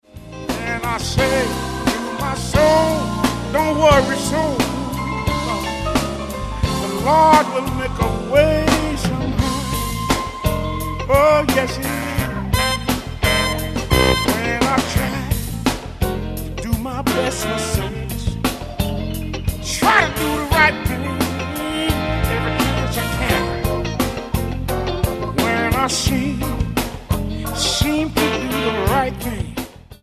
Genre: Blues
vocals
keyboards
guitar
bass
drums
trumpet
tenor sax
tenor & baritone sax